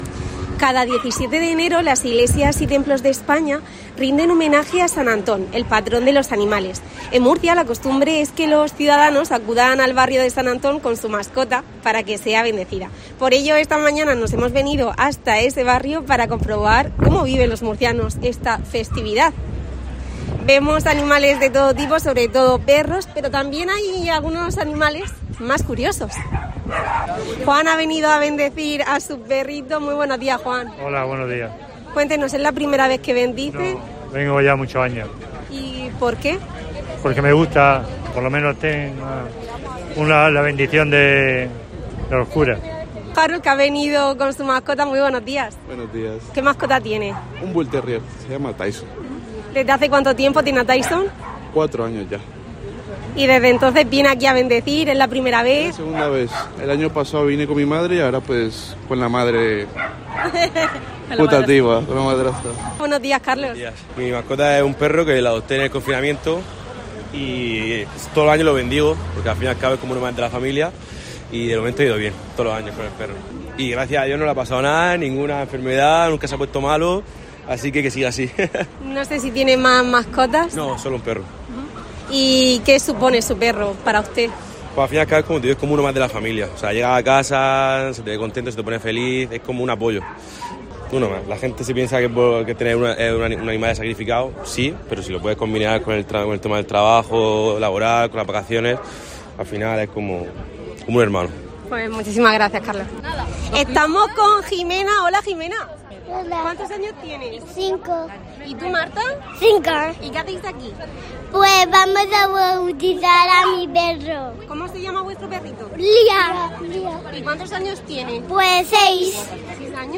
Murcia ha bendecido esta mañana a sus mascotas. Perros, gatos y animales muy curiosos se han dado cita en la ermita de San Antón